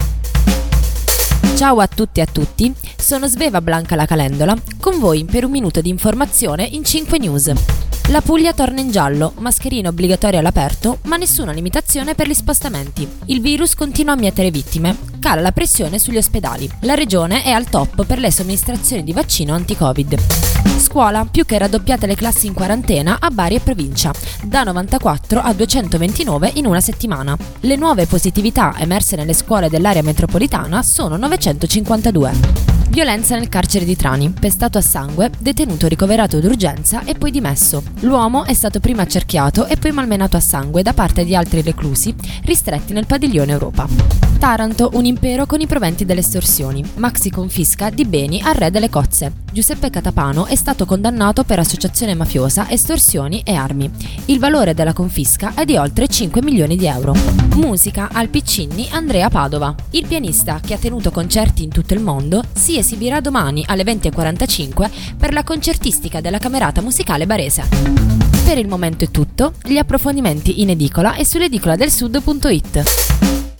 Giornale radio alle ore 13.